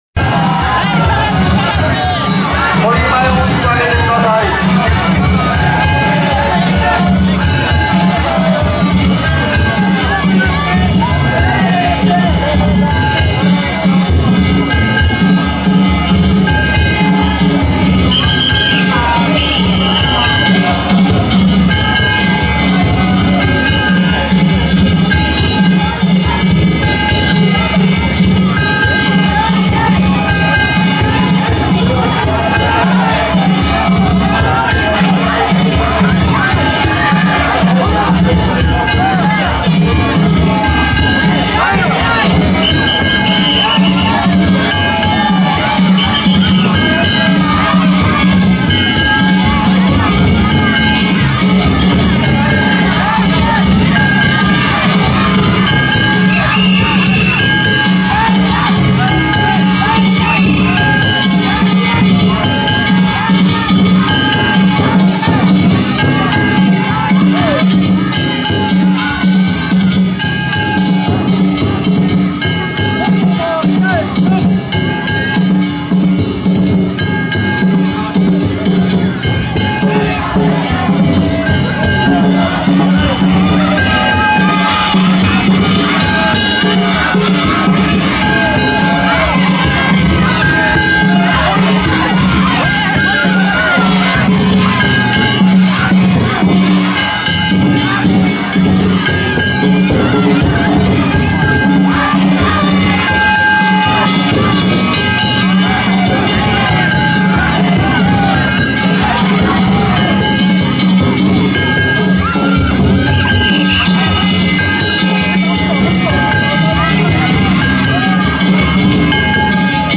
平成２９年７月１６日、生野区の疎開道路パレードを見に行ってきました。